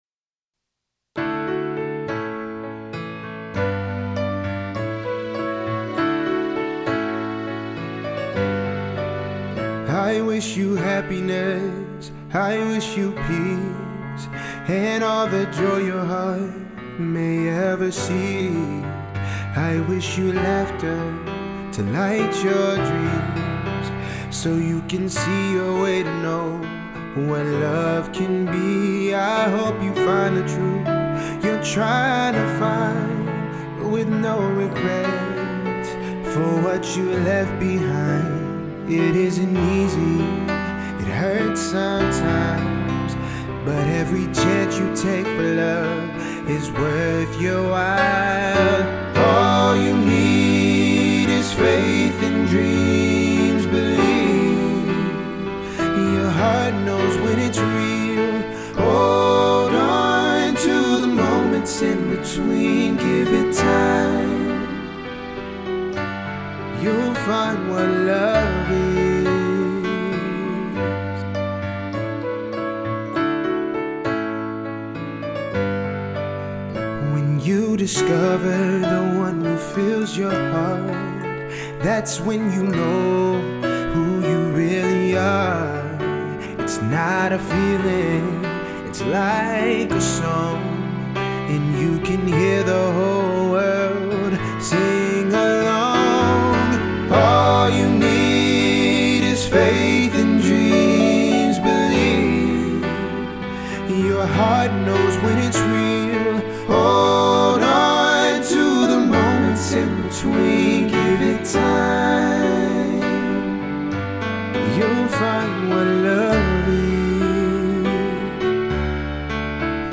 (country/AC)